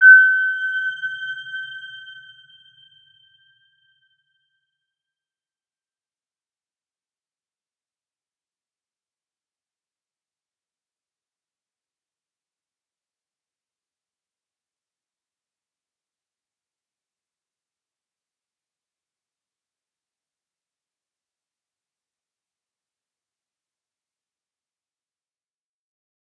Round-Bell-G6-p.wav